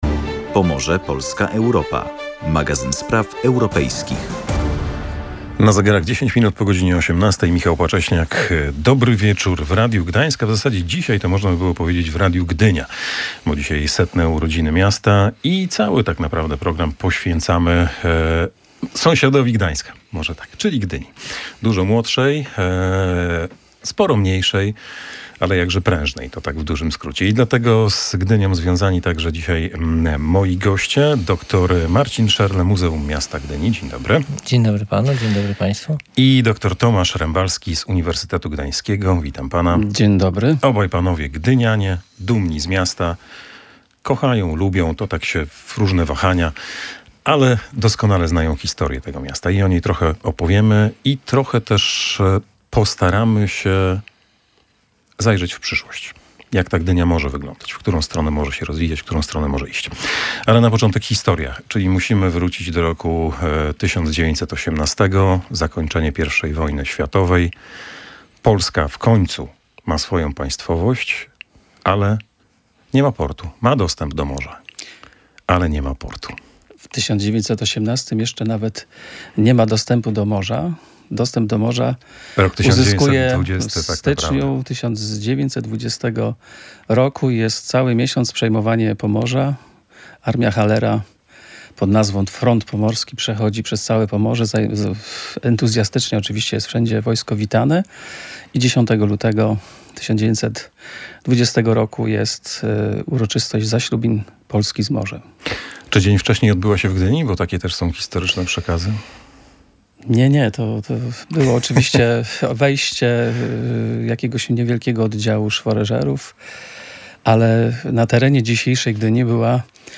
W setną rocznicę nadania Gdyni praw miejskich rozmawialiśmy w audycji „Pomorze, Polska, Europa” o mieście z morza i marzeń.